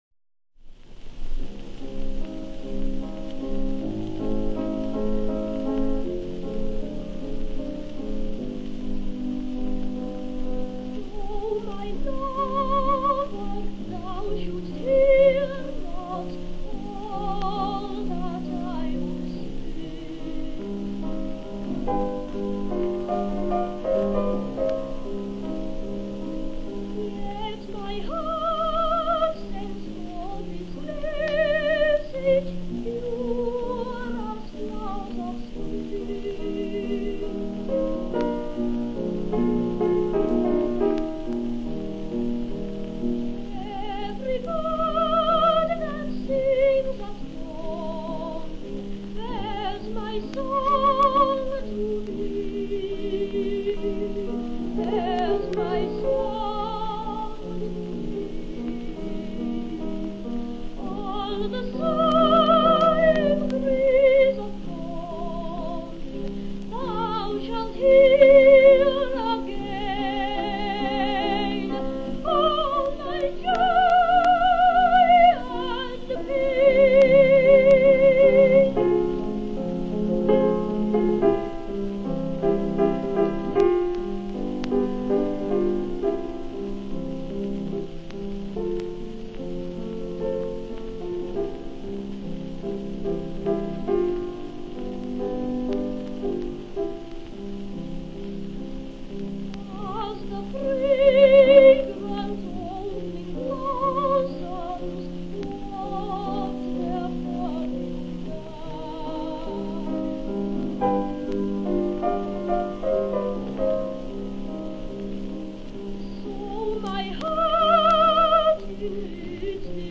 Great Boy Sopranos of the Early Twentieth Century